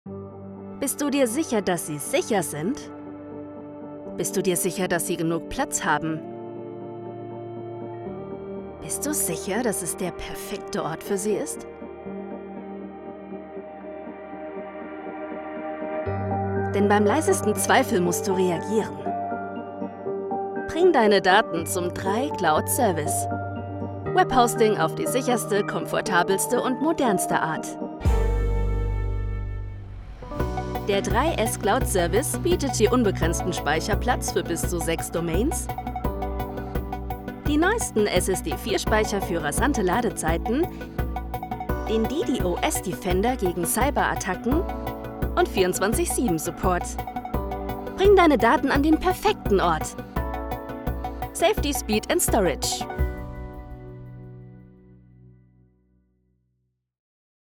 Lebendig, Vielseitig, Kompetent
Sprecherin